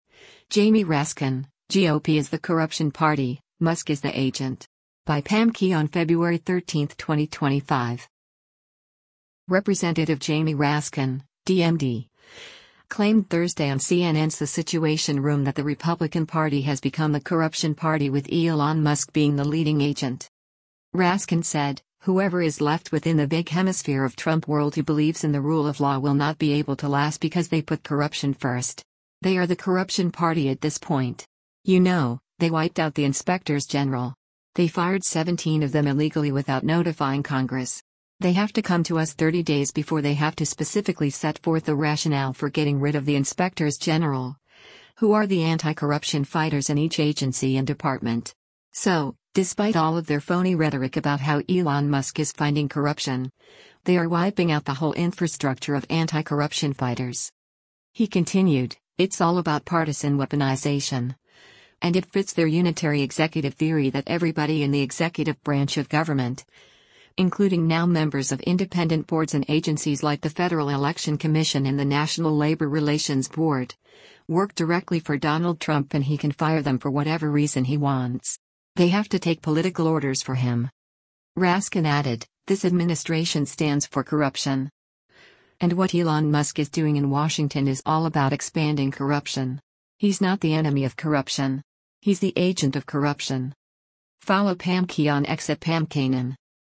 Representative Jamie Raskin (D-MD) claimed Thursday on CNN’s “The Situation Room” that the Republican Party has become the “corruption party” with Elon Musk being the leading agent.